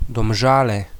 Domžale (Slovene pronunciation: [dɔmˈʒàːlɛ]
Sl-Domzale.oga.mp3